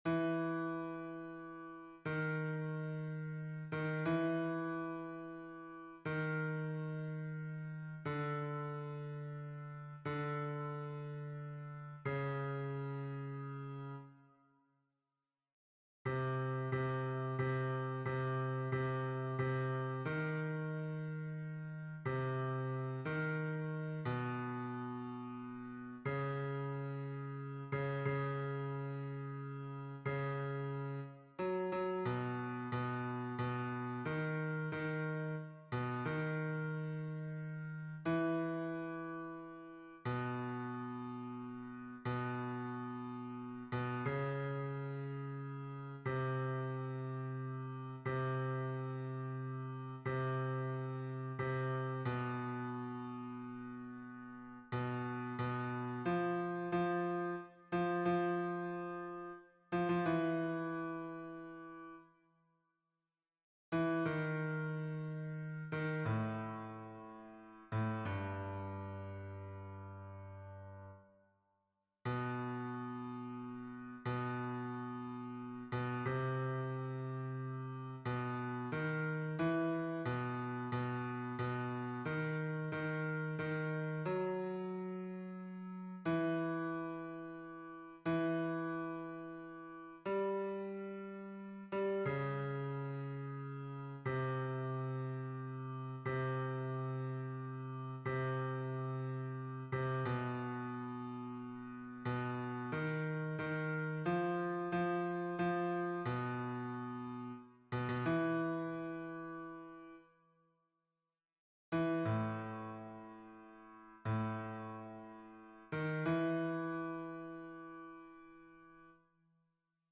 MP3 versions rendu voix synth.
Hommes